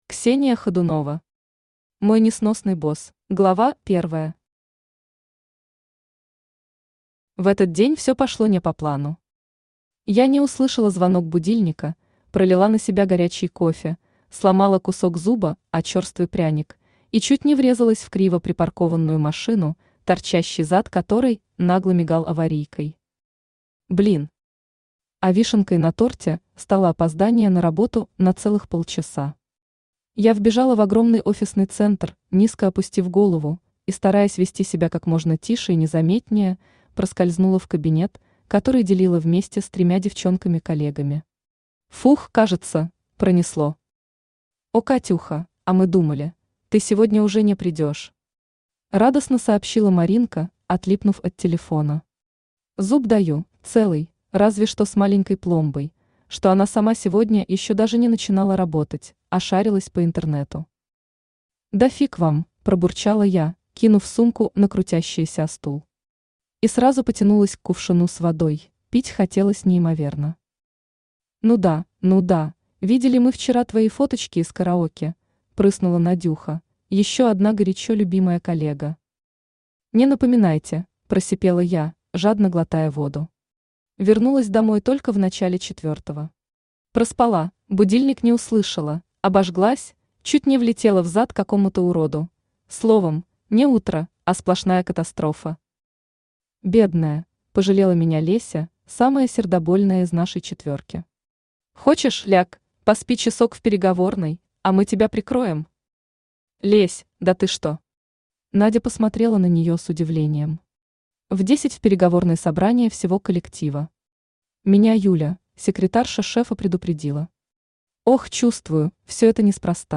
Аудиокнига Мой несносный босс | Библиотека аудиокниг
Aудиокнига Мой несносный босс Автор Ксения Александровна Хадунова Читает аудиокнигу Авточтец ЛитРес.